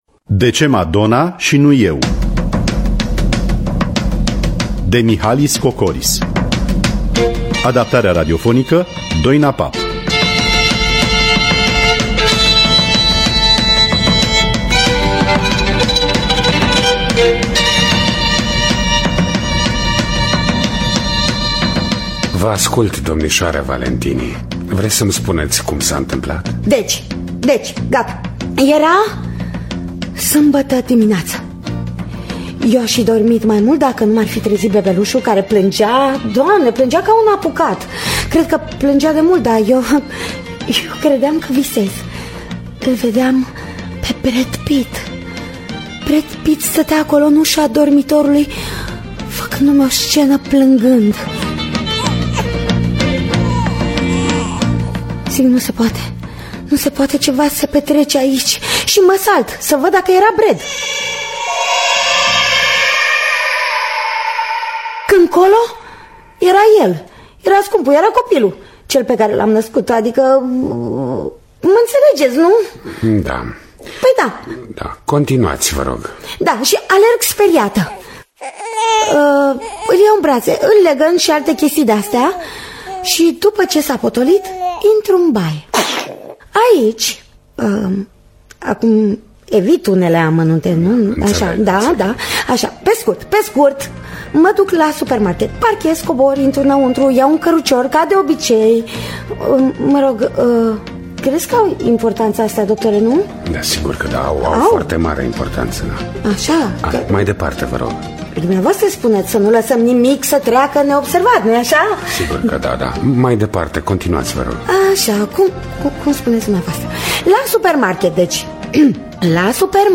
Adaptarea radiofonica